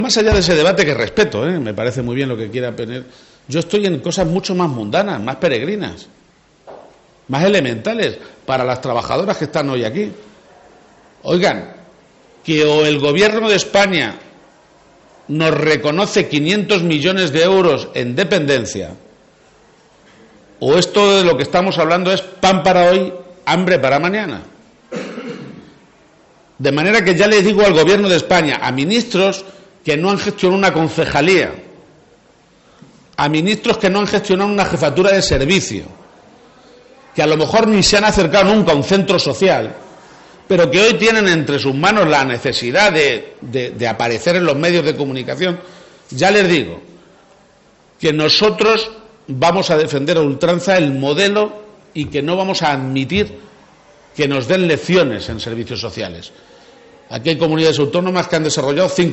Presidente Miércoles, 18 Mayo 2022 - 11:00am El presidente de Castilla-La Macha ha recordado esta mañana en Manzanares (Ciudad Real), en el transcurso de la inauguración de la rehabilitación 'Casa Josito', que el Gobierno de España adeuda 500 millones de euros en dependencia a la Comunidad Autónoma y que no va a cesar en su empeño de demandarlo. garcia_page_500_millones_deuda.mp3 Descargar: Descargar